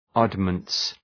oddments.mp3